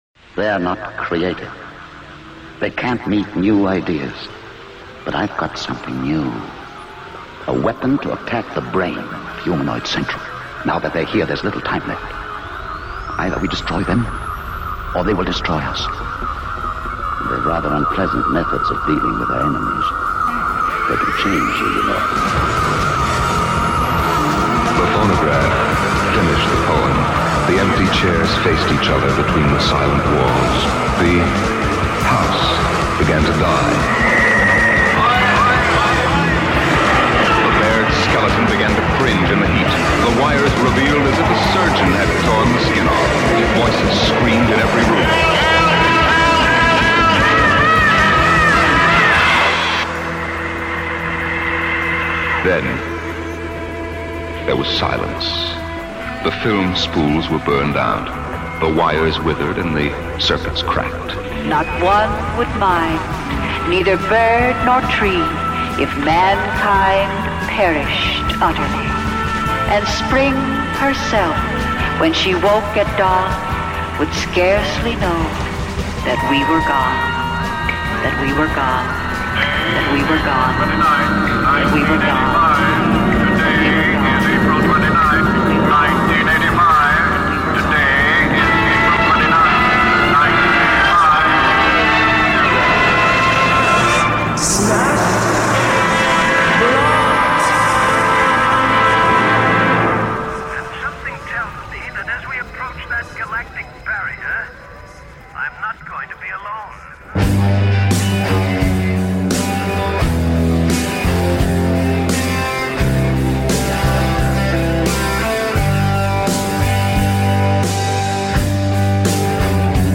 Jazz, punk, krautrock, psychedelia, noise, hardcore, folk, avant-garde & weird bleak rock & roll.